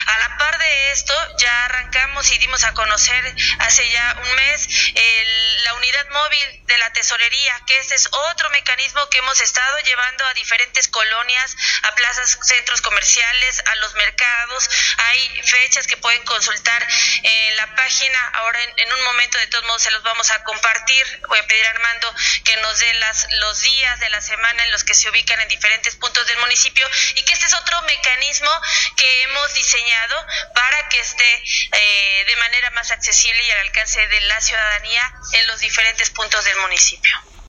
En conferencia de prensa, el tesorero municipal, Armando Morales Aparicio, indicó que la “Tesorería Móvil” acudirá al parque de El Carmen en un horario de 9 a 3 de la tarde.